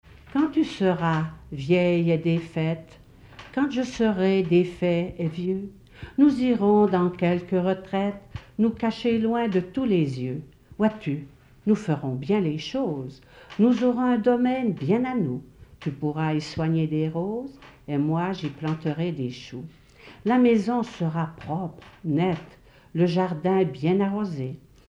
Poème
Catégorie Témoignage